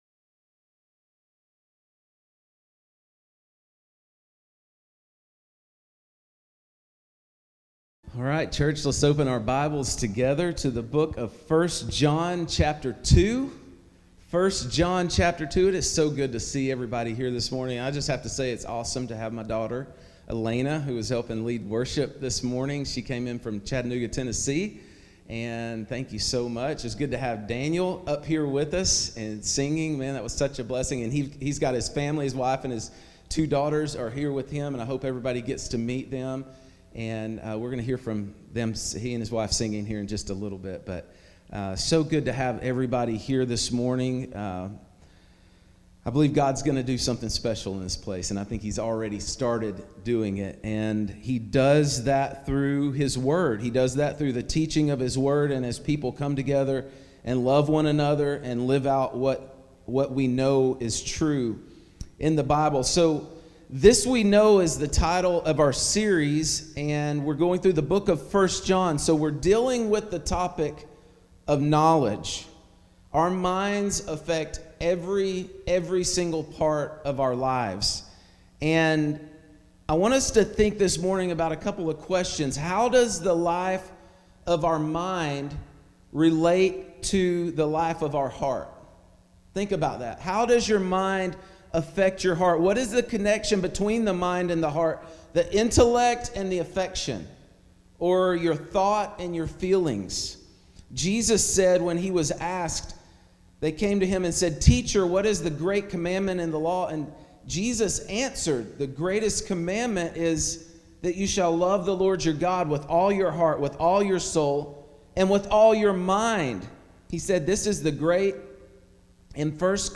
A Sermon Series Through First John 1 John 2:7-17